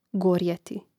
gòrjeti gorjeti